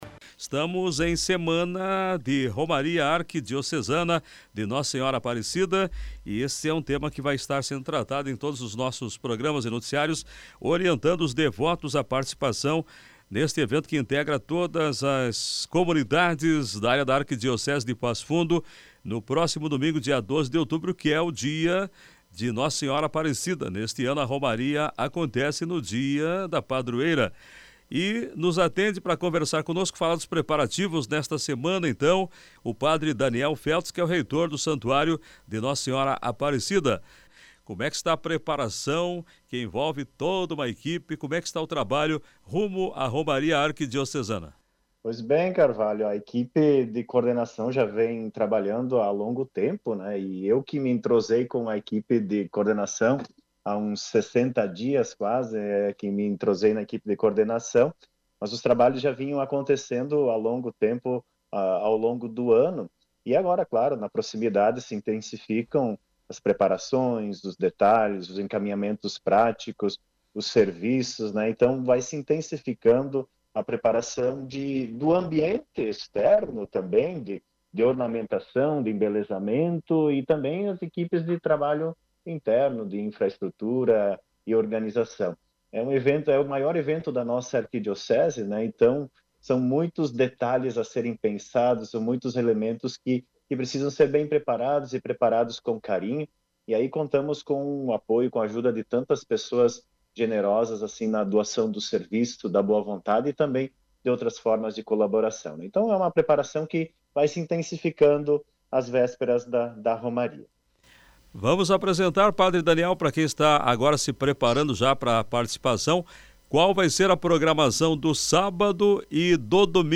concedeu entrevista à Rádio Planalto News (92.1) sobre a preparação.